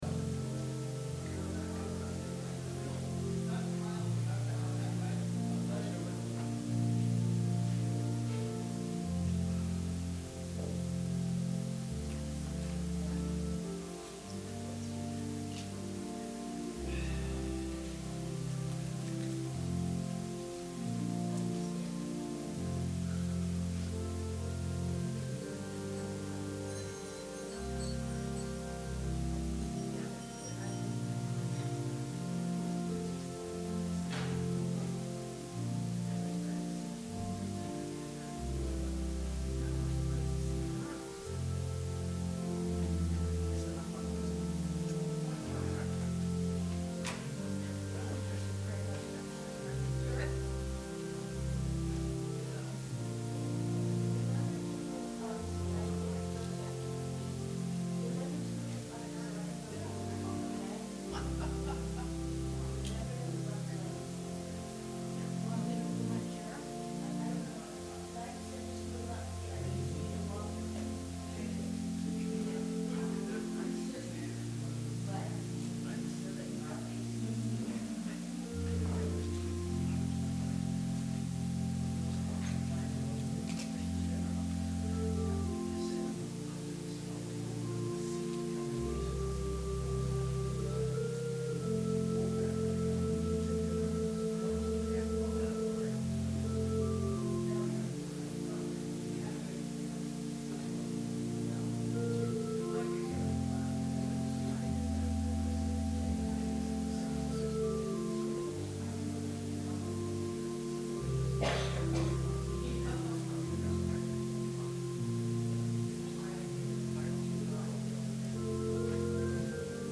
Sermon – March 8, 2020 – Advent Episcopal Church
advent-sermon-march-8-2020.mp3